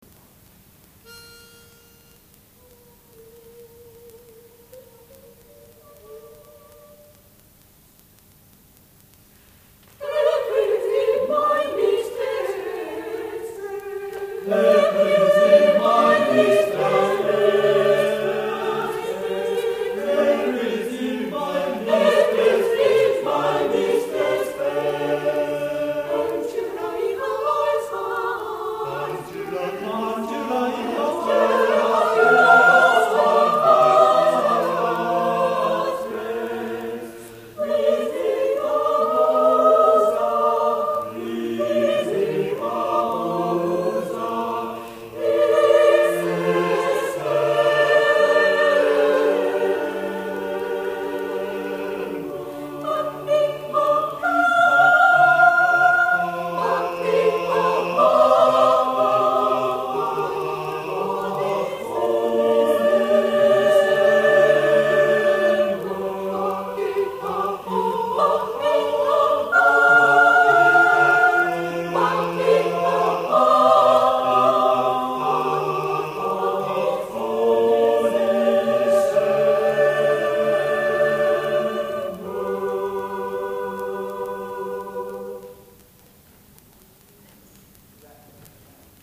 第33回野田市合唱祭
野田市文化会館